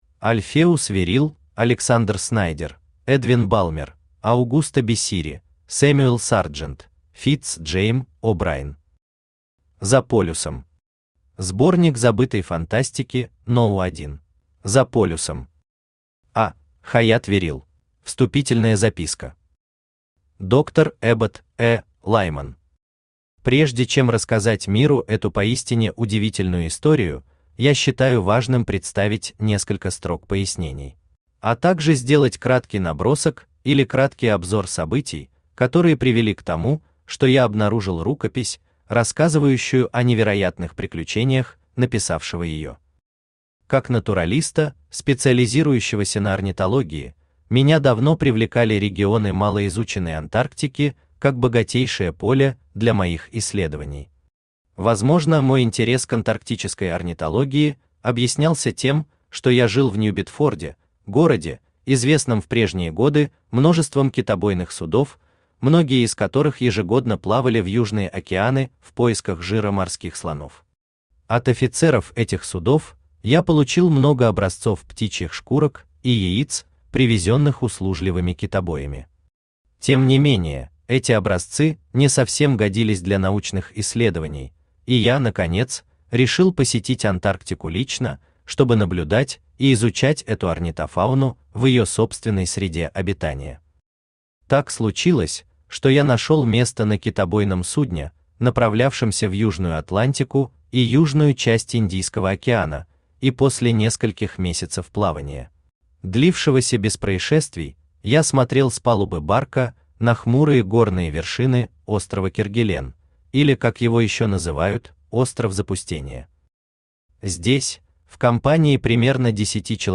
Аудиокнига Сборник забытой фантастики №1 | Библиотека аудиокниг
Aудиокнига Сборник забытой фантастики №1 Автор Альфеус Хаятт Веррилл Читает аудиокнигу Авточтец ЛитРес.